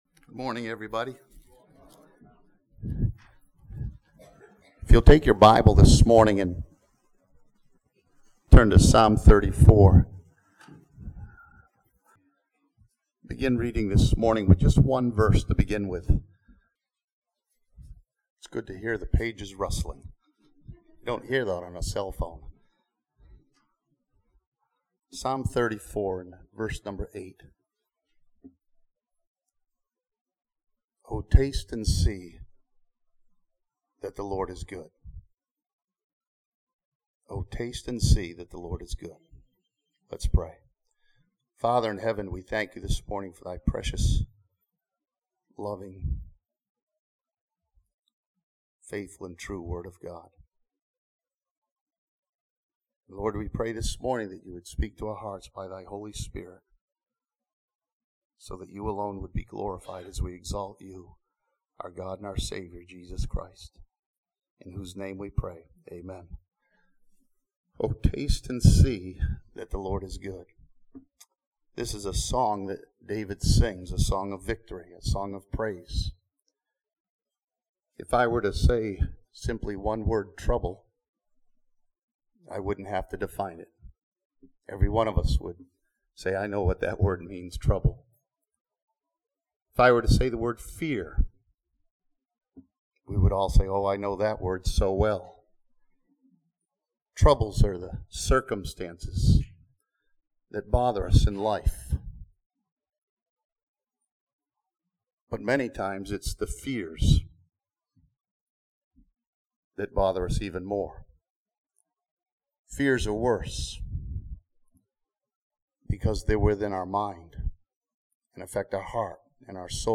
This sermon from Psalm 34 encourages believers to taste and see that the Lord is always good to us.